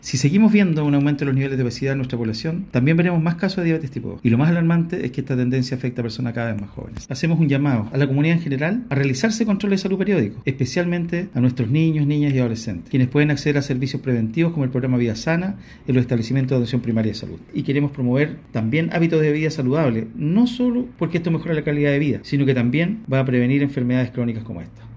Así lo señaló el director del Servicio de Salud Araucanía Sur, Vladimir Yáñez, quien además hizo un llamado a la población, especialmente a los niños, quienes deben tener sus chequeos médicos al día.